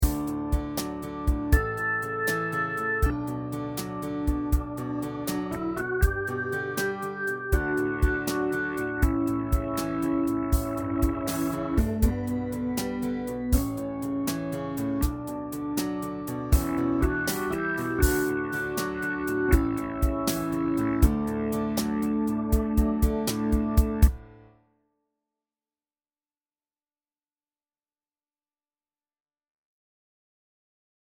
12/8 time.